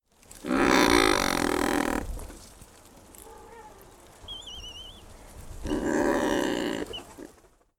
На этой странице вы можете послушать и скачать звуки гагарки – морской птицы, известной своим характерным голосом.
Звук гагарки: голос Razorbill